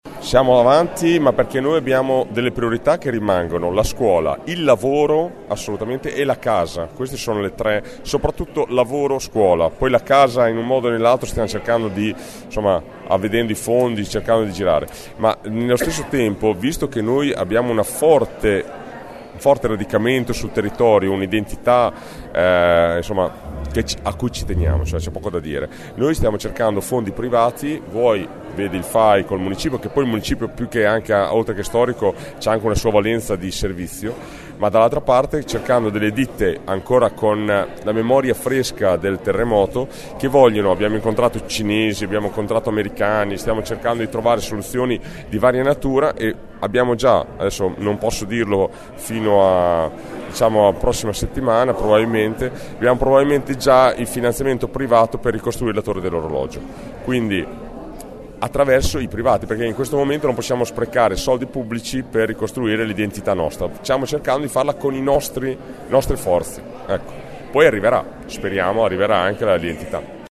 Alla conferenza stampa di presentazione dell’iniziativa, è intervenuto anche il sindaco di Finale, Fernando Ferioli, che ha ringraziato il Fai del contributo: “Le priorità rimangono le scuole e il lavoro ma vogliamo ricostruire i simboli della nostra identità”.